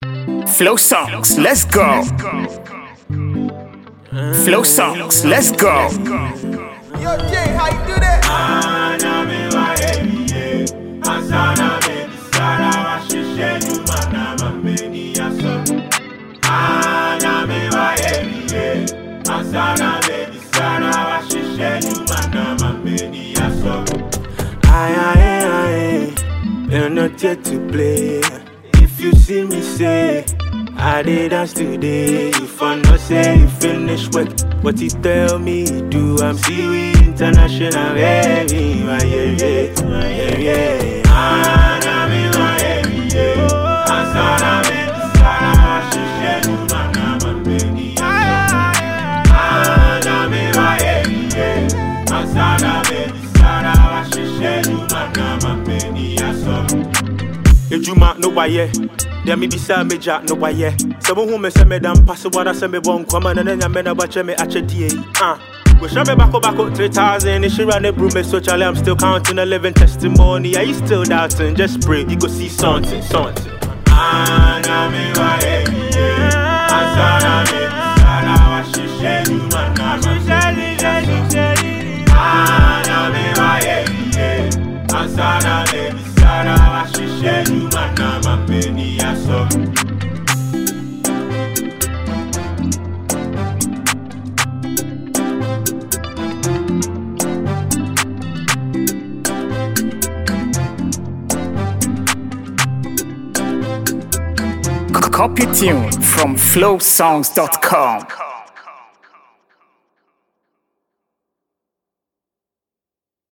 It is a catchy and lively melody.
Highlife and Afrobeat